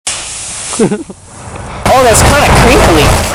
Play, download and share Cinkly original sound button!!!!
crinkly.mp3